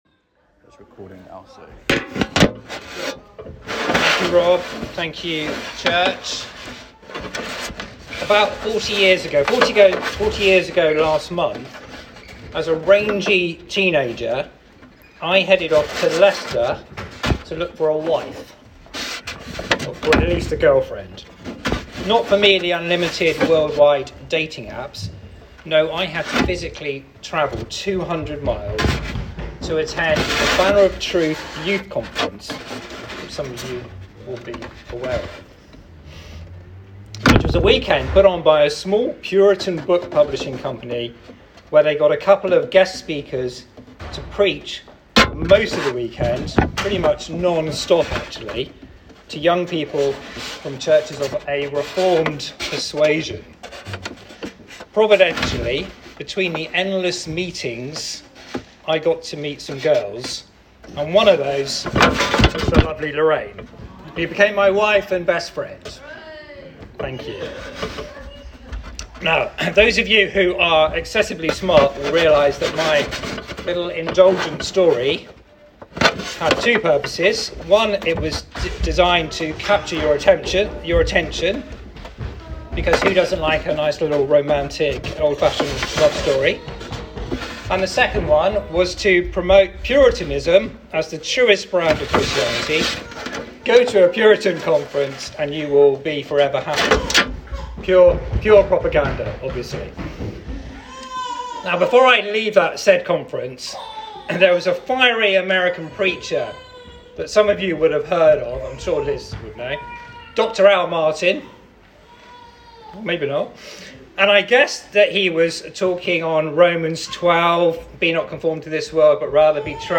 All Sermons Royal Priesthood & Holy Nation